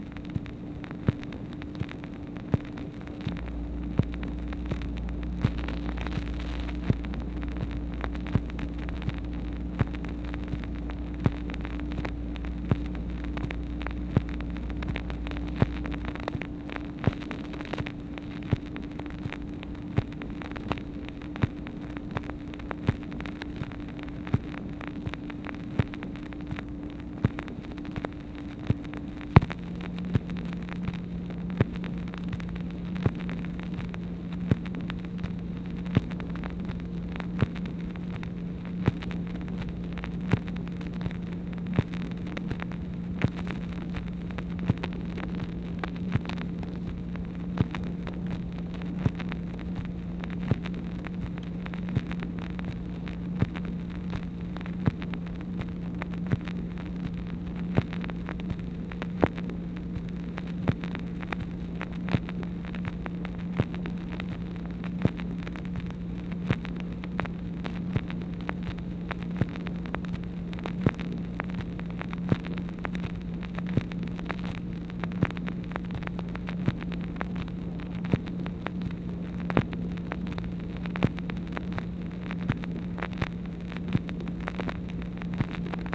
MACHINE NOISE, June 9, 1964
Secret White House Tapes | Lyndon B. Johnson Presidency